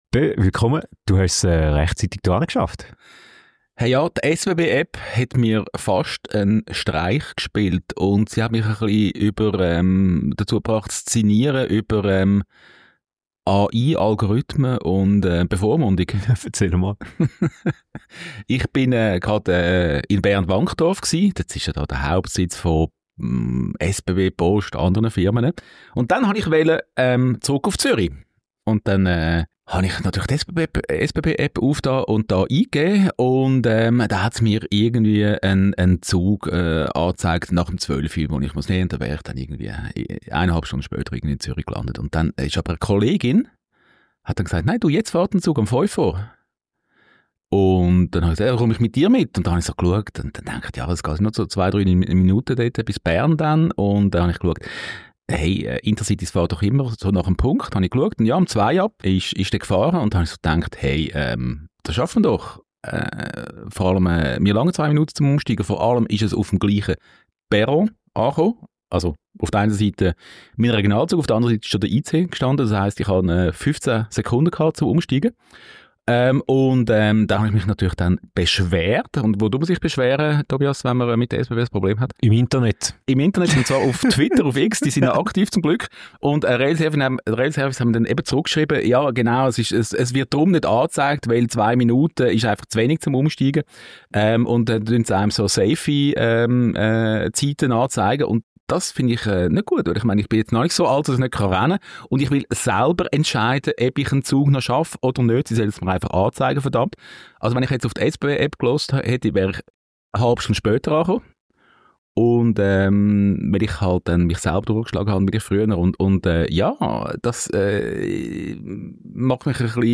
In Interviews mit führenden Expertinnen und -Experten bieten sie dir zudem tiefgehende Einblicke und spannende Hintergrundinformationen.